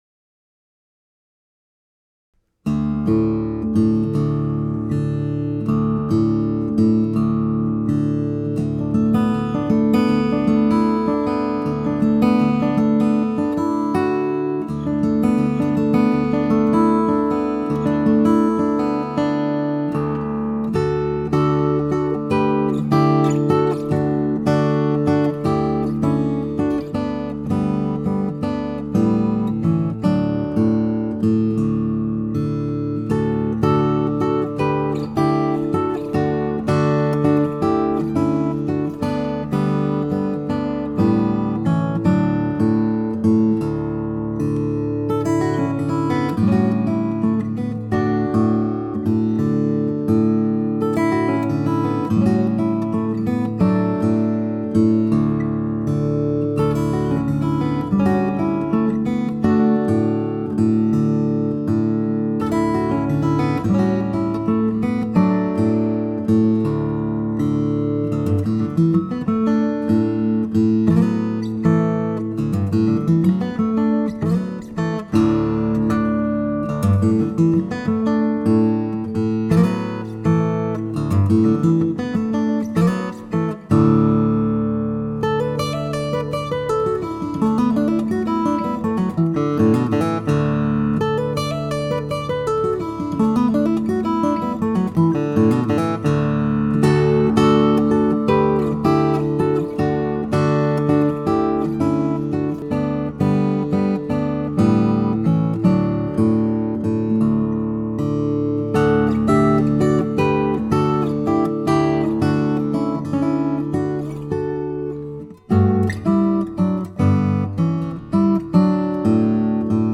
Solo Guitar
solo finger-style guitar